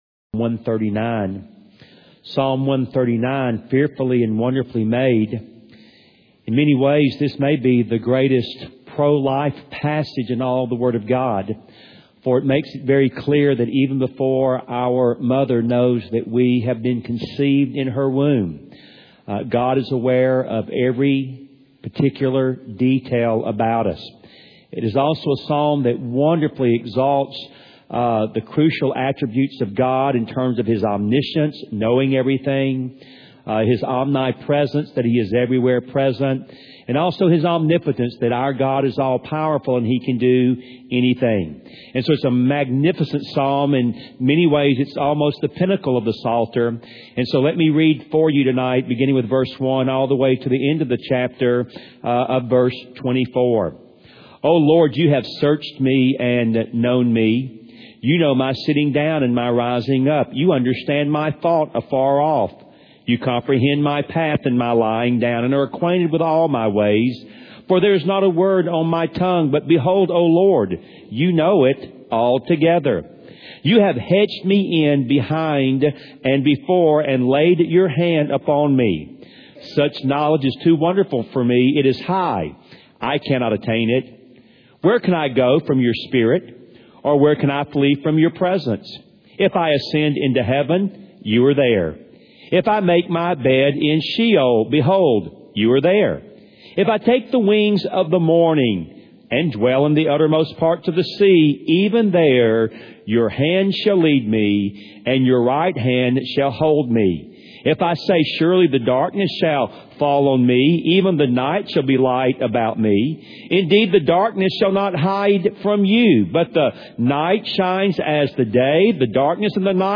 Part of a series entitled “Through the Psalms” delivered at Wake Cross Roads Baptist Church in Raleigh, NC